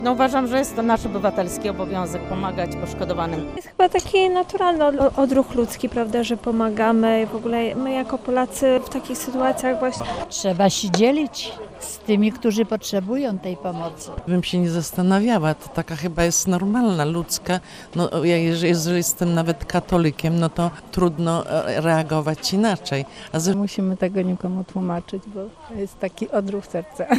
Rozmawialiśmy z parafianami z kościoła św. Jana Kantego w Legionowie.